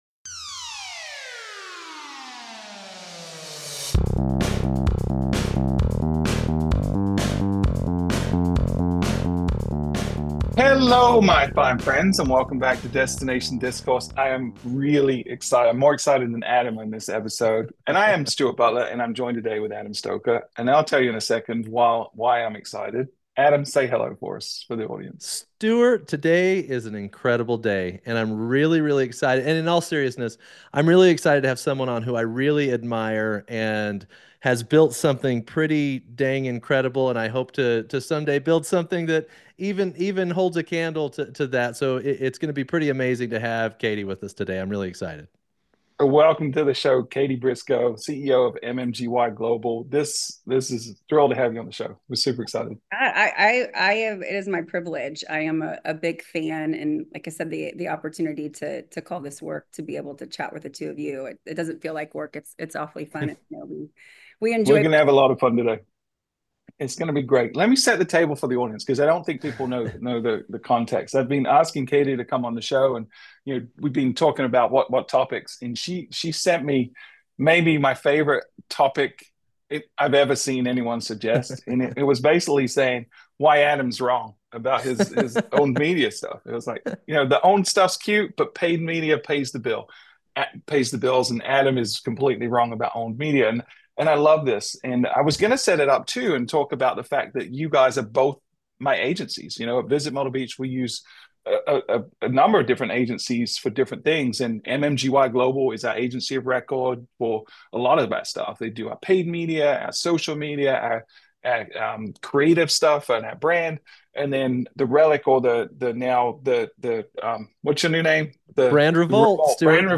The debate focuses on the ongoing shift between paid and owned media and whether DMOs are making the right investments in their marketing strategies.